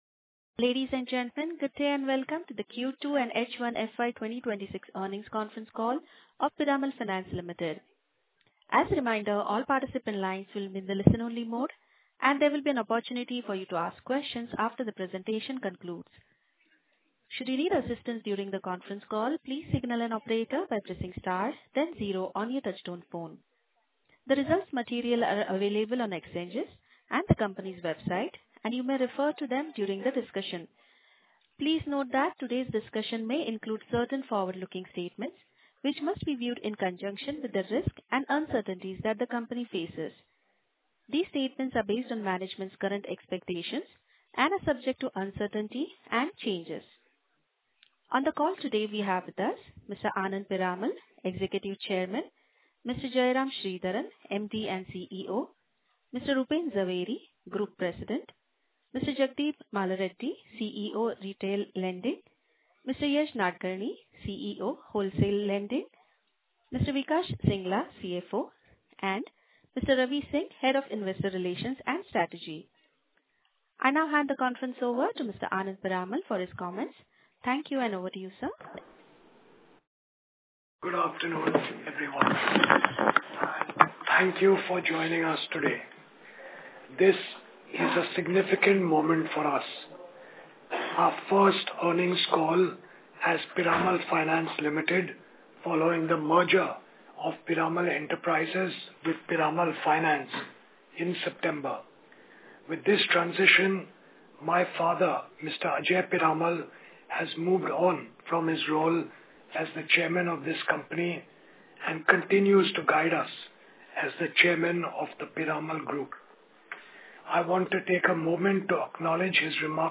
Q2 FY2026 Earnings Conference Call